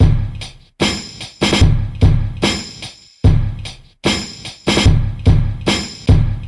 Loops, breaks